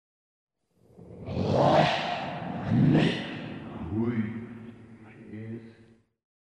Genere: heavy metal
Incomprensibile